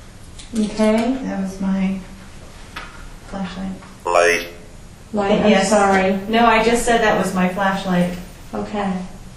This audio was caught in the officers quarters (more like a house). I was messing around with my flashlight and our spirit box speaks up!